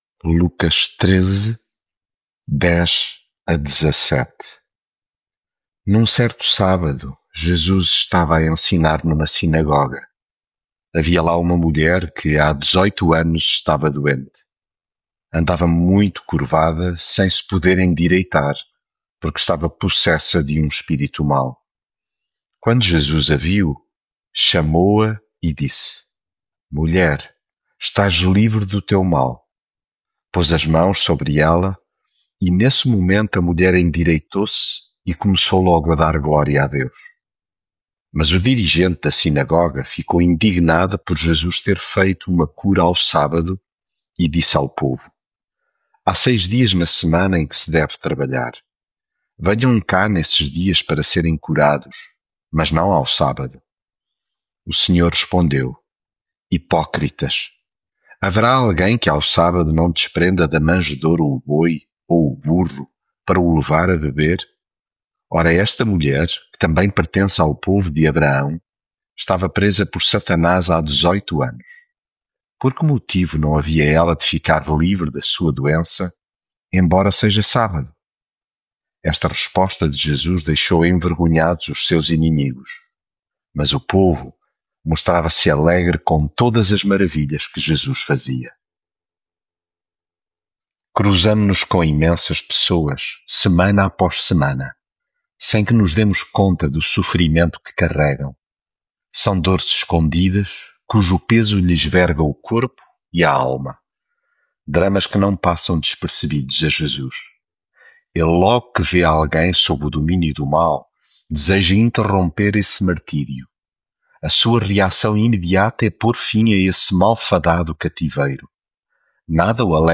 devocional Lucas leitura bíblica Um sábado, estava Jesus a ensinar numa sinagoga, quando viu uma mulher que andava curvada, sem se poder endireitar, havia dezoito...